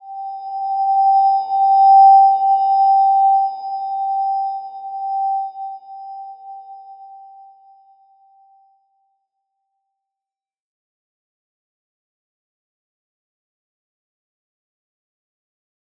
Slow-Distant-Chime-G5-mf.wav